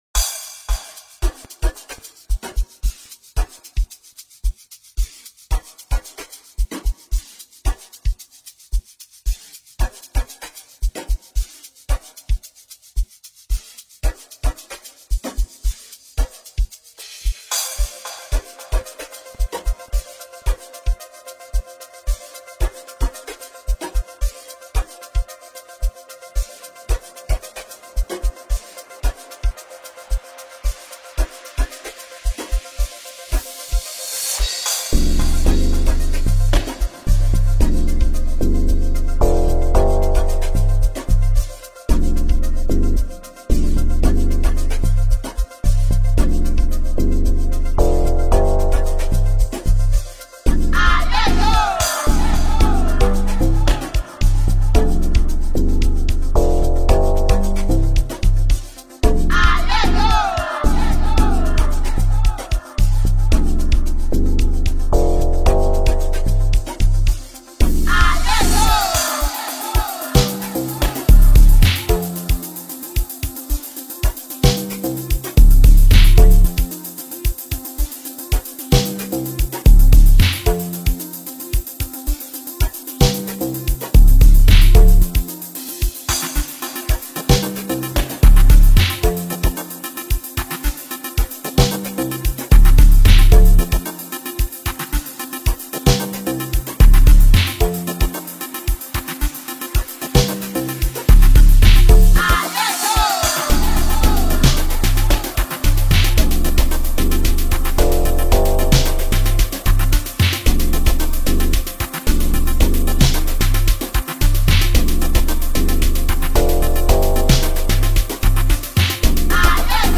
groovy Piano vibes